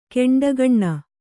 ♪ keṇḍagaṇṇa